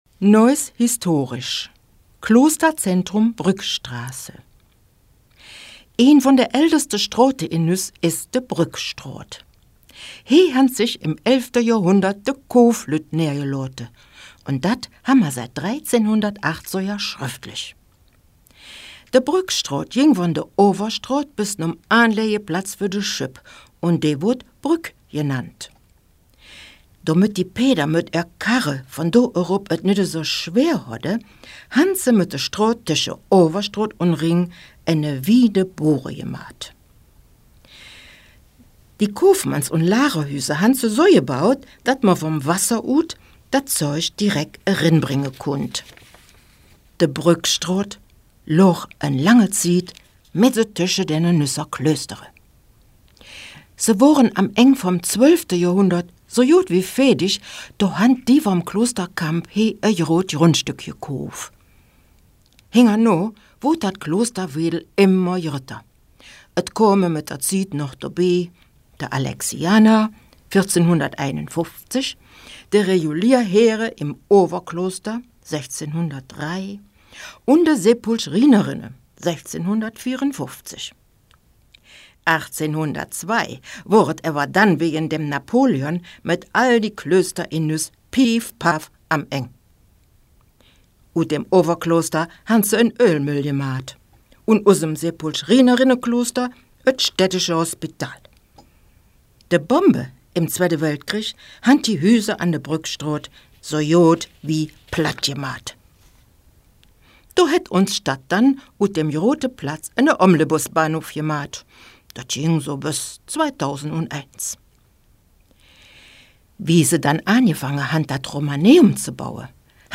Audio Guide (Nüsser Platt)
neuss-historisch--klosterzentrum-brueckstrasse--platt.mp3